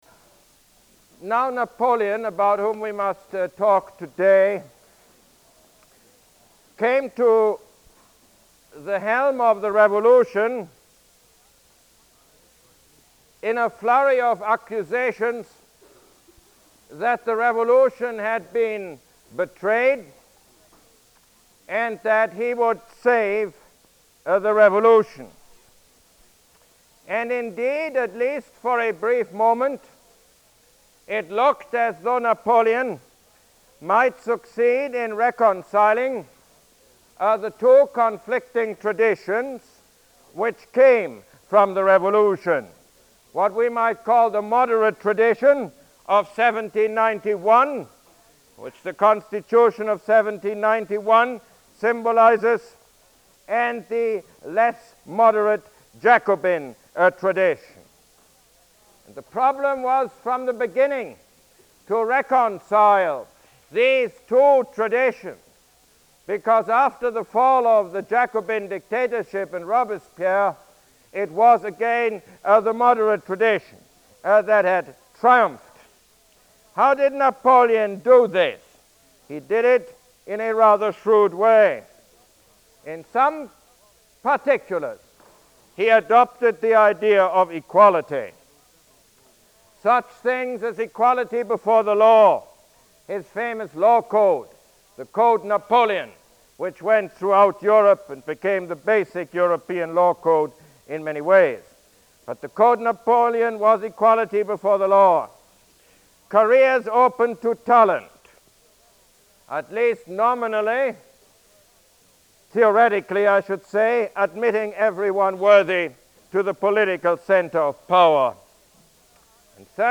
Lecture #25 - Napoleon